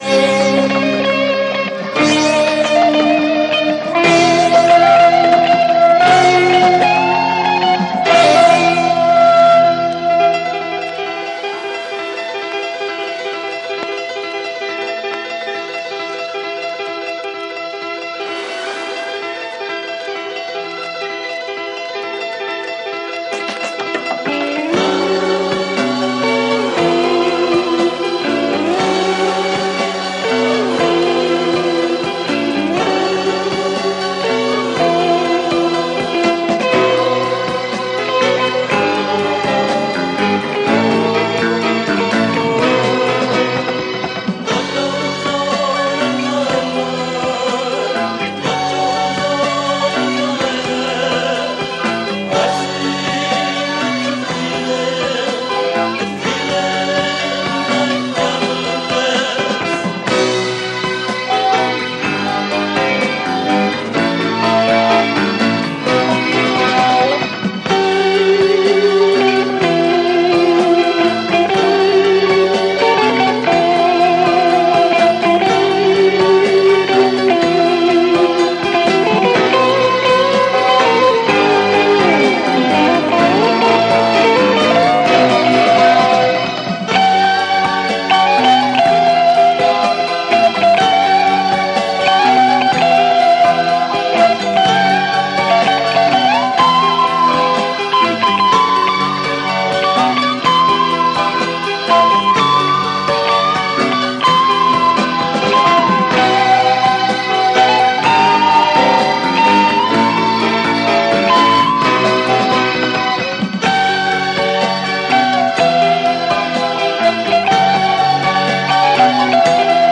German Progressive Synthé